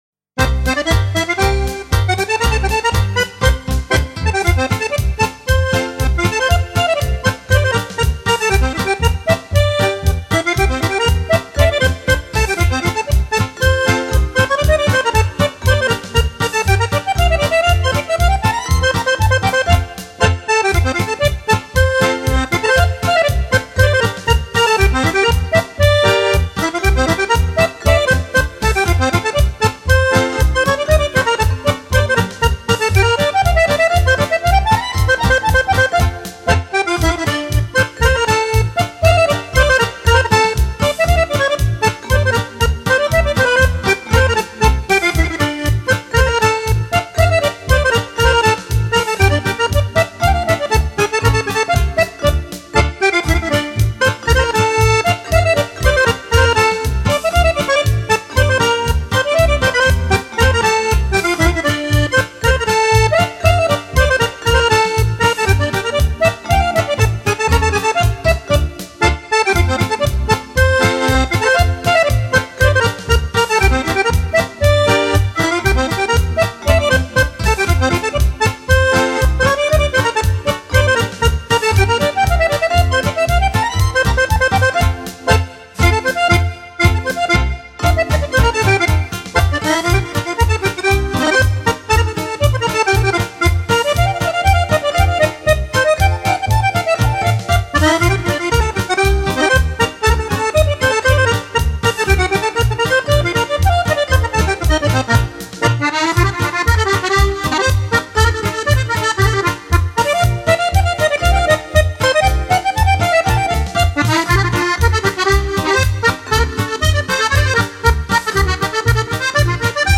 【简*介】 欢快、热情、繁复变化的舞步，演绎着似火的浓情与缠绵来自手风琴的欢笑！
欧洲风格的音乐素材，融合现代元素，充满感性的欧式舞曲。